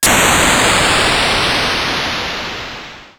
GasReleasing12.wav